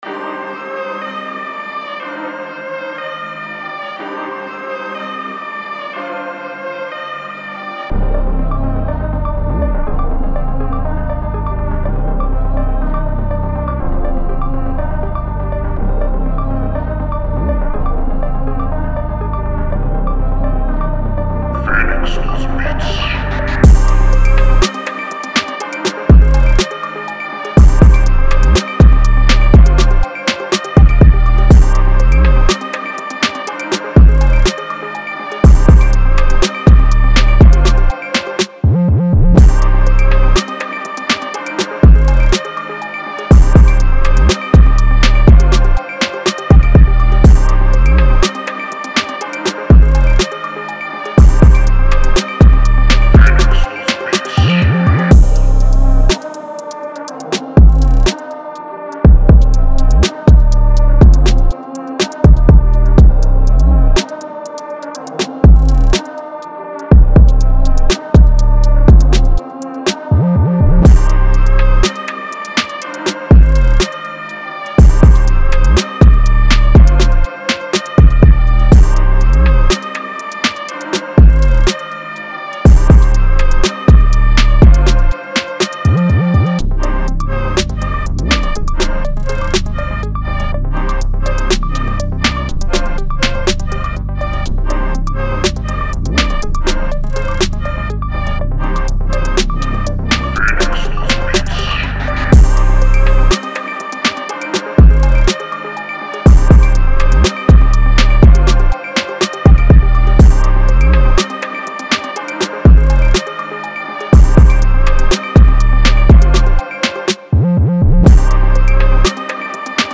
Hard Trap Instrumental